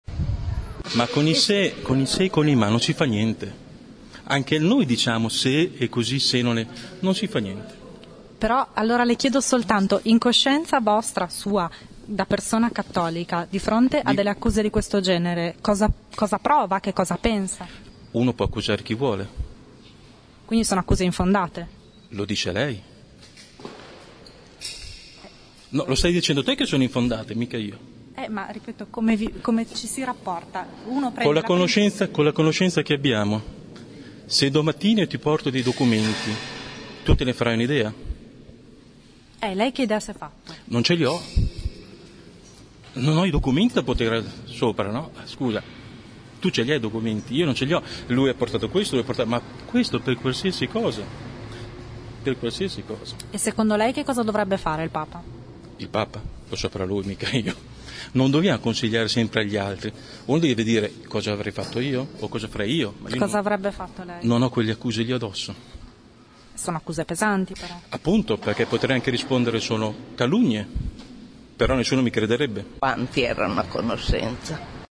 Ascolta la voce del diacono: